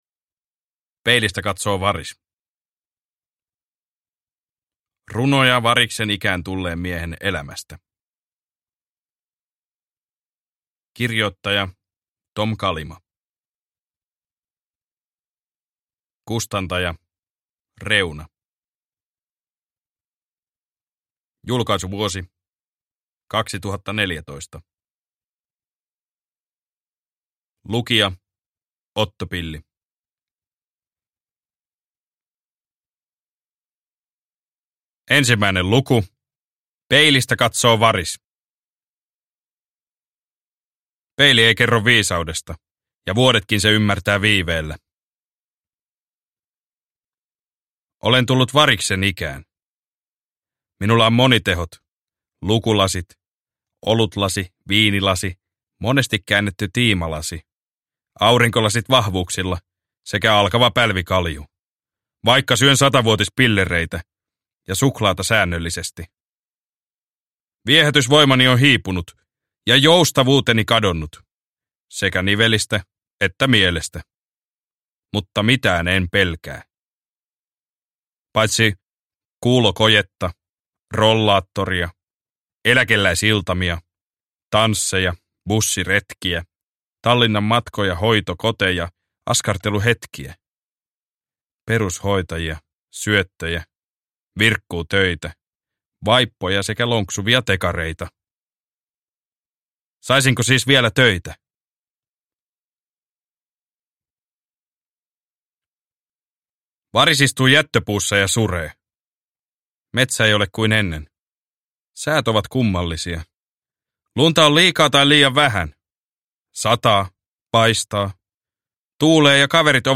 Peilistä katsoo varis – Ljudbok – Laddas ner